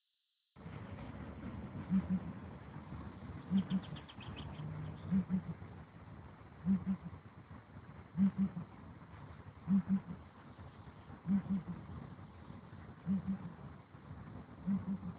Le crôa du Calao
La nuit tombe, le calao terrestre arpente son territoire en faisant retentir son cri entêtant (juin 2016)
calao.mp3